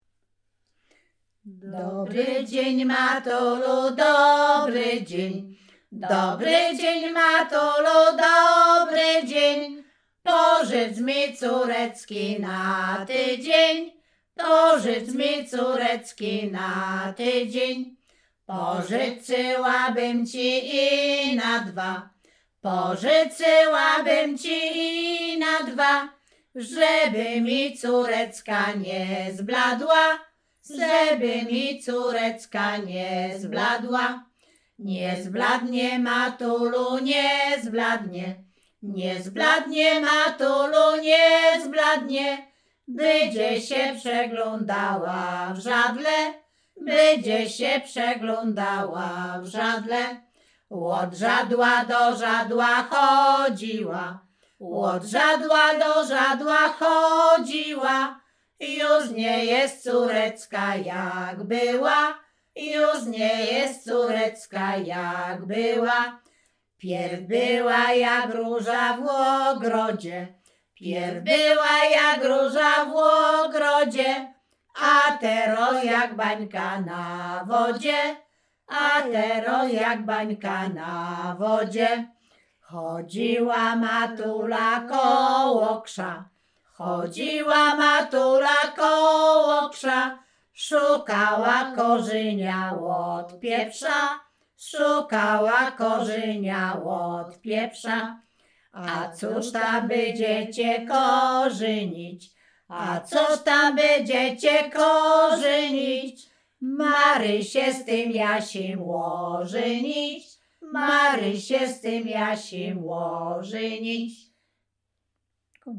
Sieradzkie
wesele weselne liryczne miłosne